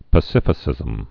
(pə-sĭfĭ-sĭzəm)